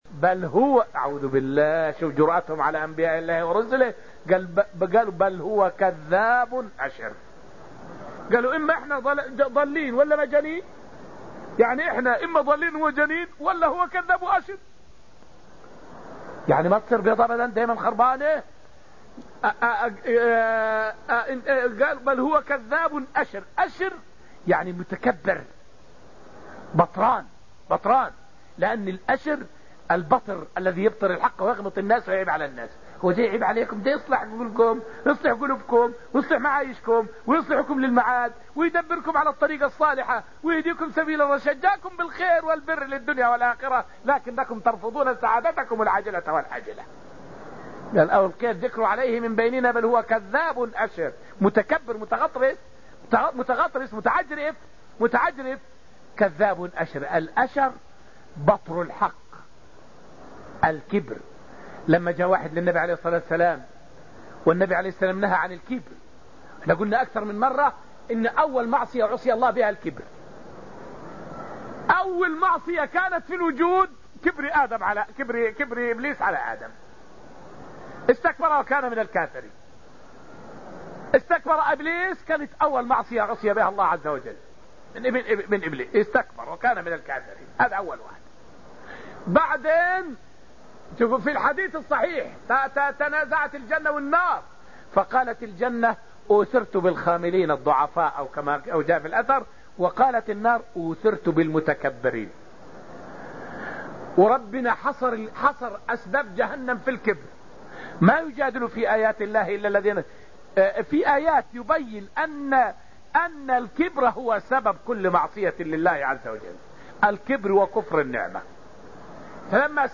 فائدة من الدرس الثالث من دروس تفسير سورة القمر والتي ألقيت في المسجد النبوي الشريف حول حال النبي صلى الله عليه وسلم مع أهله.